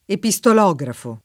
epistolografo [ epi S tol 0g rafo ] s. m.